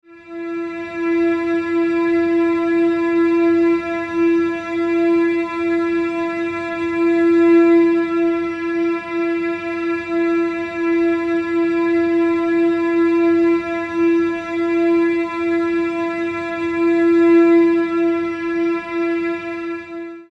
Music tones for String Animation Below
22_Medium_E.mp3